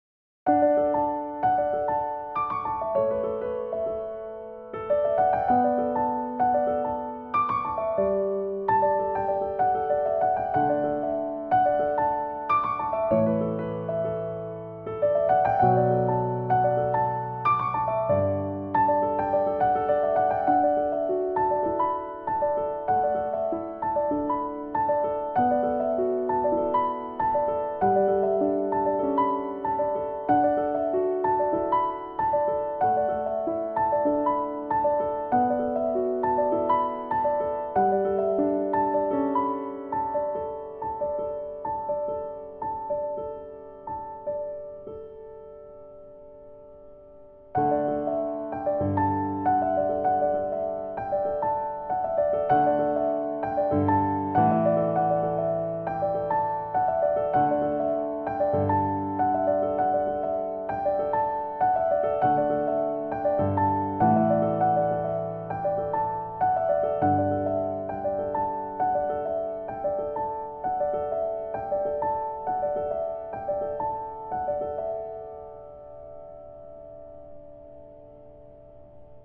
時にシンプルで 時に複雑なひびき 重なりあう音色の変化 ジャジーなテンション 人間らしいアナログな間